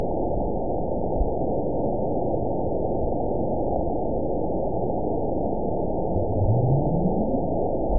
event 920428 date 03/24/24 time 23:07:24 GMT (1 year, 1 month ago) score 9.51 location TSS-AB02 detected by nrw target species NRW annotations +NRW Spectrogram: Frequency (kHz) vs. Time (s) audio not available .wav